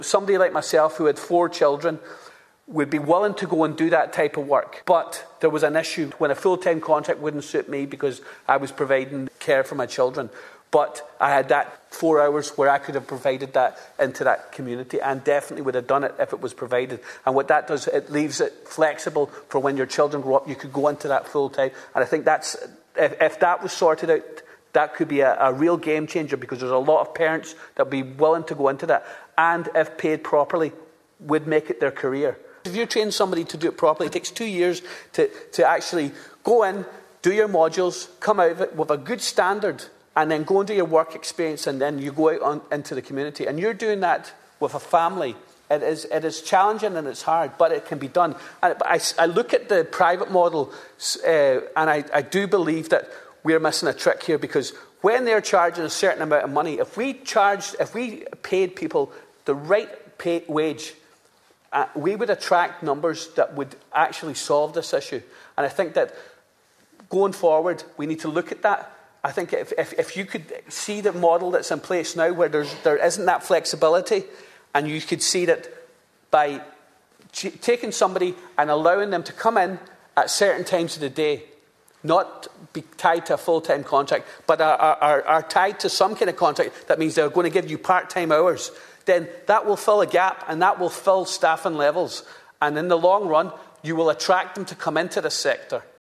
Deputy Charles Ward told a debate on the second stage of an amendment to the 2025 Home Support Providers Bill that when he was a trained Healthcare Assistant, he was also looking after his own children, and so turned down the offer of a full-time job from the HSE.